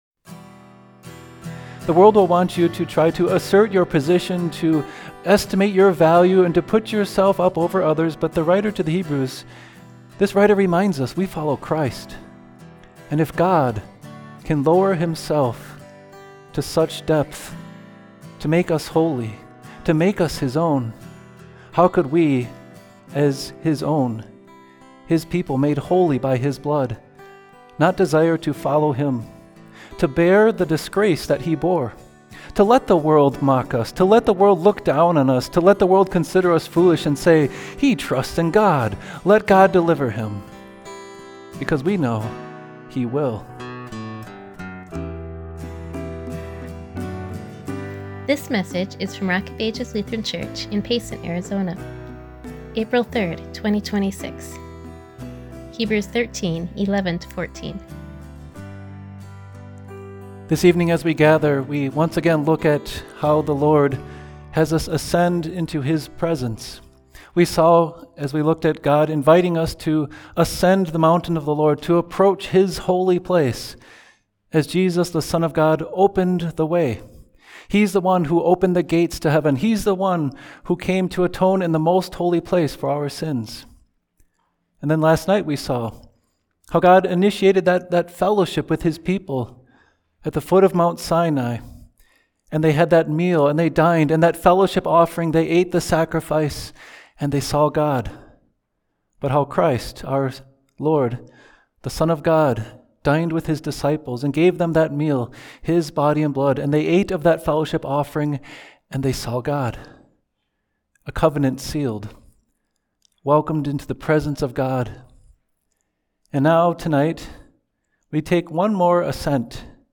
Hebrews 13:11-14 ● April 3, 2026 ● Series for Holy Week ● Listen to sermon audio